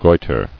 [goi·ter]